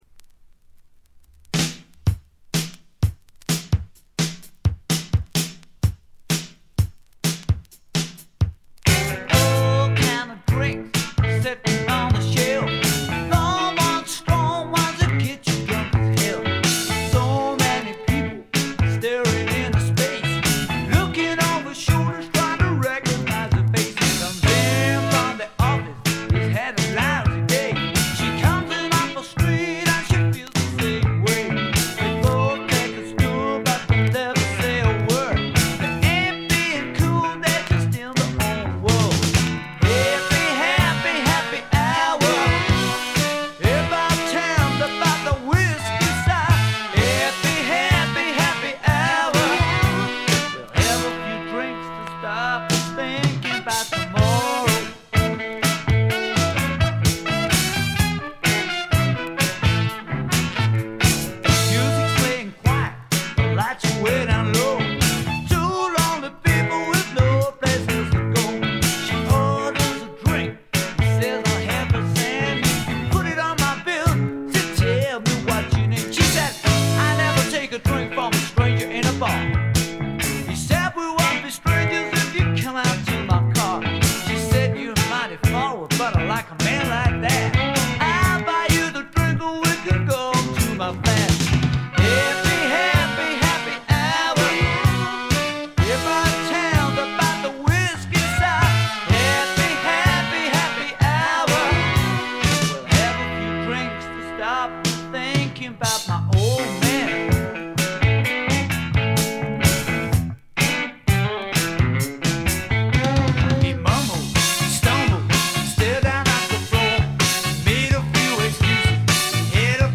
二人のホーンセクションが実によく効いてます。
試聴曲は現品からの取り込み音源です。